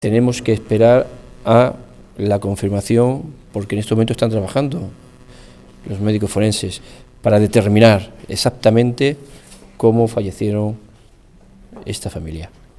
Declaraciones Delegado del Govierno en CLM 4